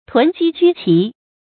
注音：ㄊㄨㄣˊ ㄐㄧ ㄐㄨ ㄑㄧˊ
囤積居奇的讀法